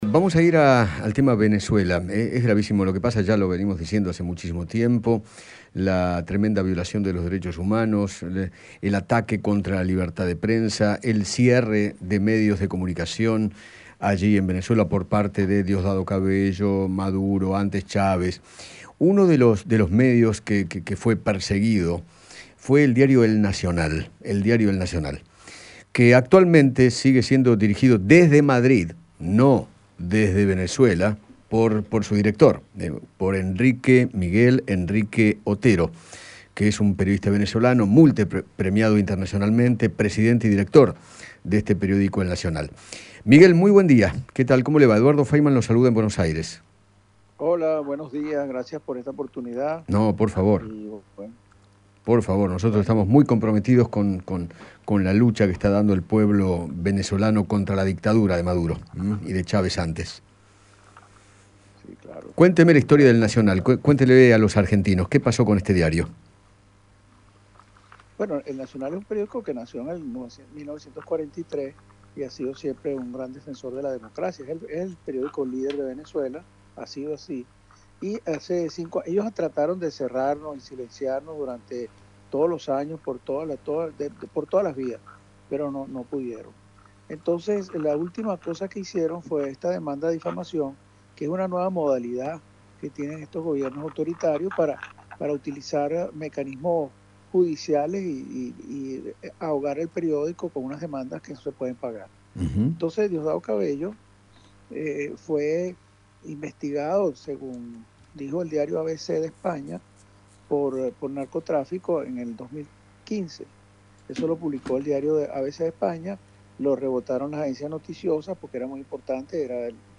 Ernesto Samper, ex presidente colombiano, dialogó con Eduardo Feinmann sobre la gravísima situación social que atraviesa aquel país y manifestó “primero la pandemia, que ha dejado empobrecimiento, desempleo y hambre; segundo, el incumplimiento sistemático por parte del Gobierno de Duque de los Acuerdos de La Habana”.